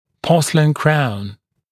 [‘pɔːsəlɪn kraun][‘по:сэлин краун]фарфоровая коронка